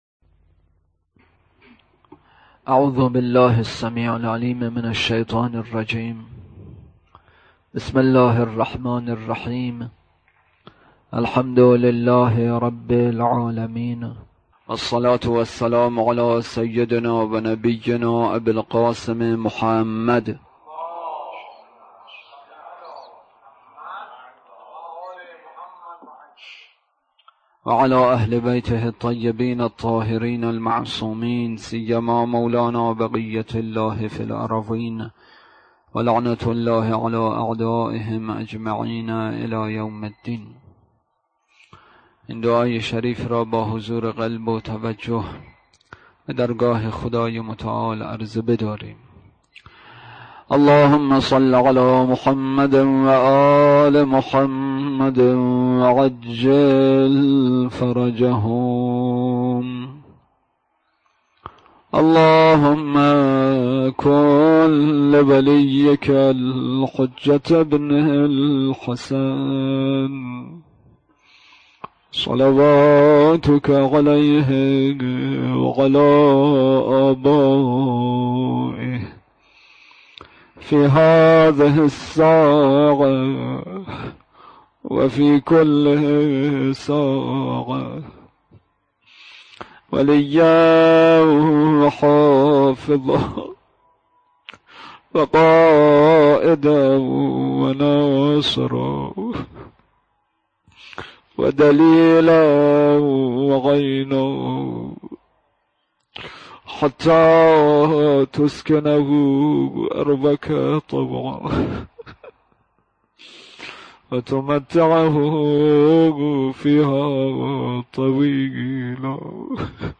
اطلاعات آلبوم سخنرانی